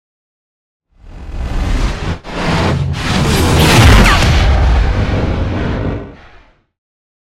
Scifi whoosh pass by shot
Sound Effects
futuristic
intense
pass by